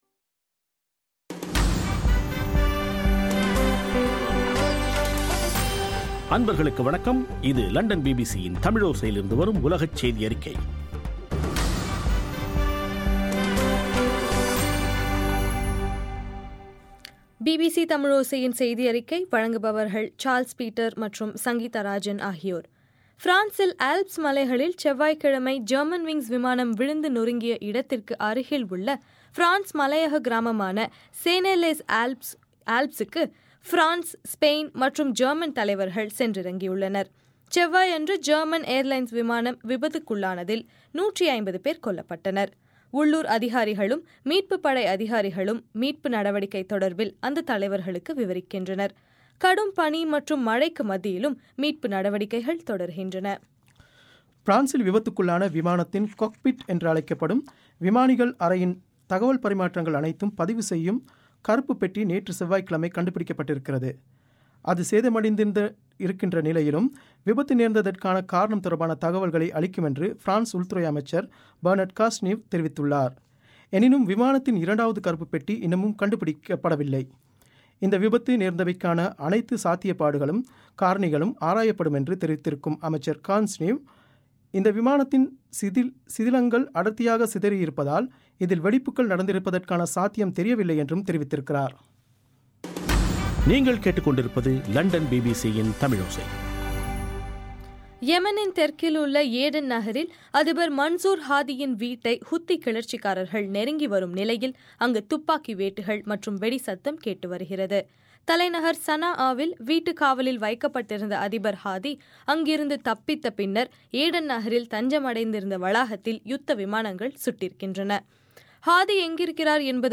இன்றைய ( மார்ச் 25) பிபிசி தமிழோசை செய்தியறிக்கை